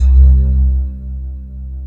Index of /90_sSampleCDs/Big Fish Audio - Synth City/CD1/Partition B/05-SYNTHLEAD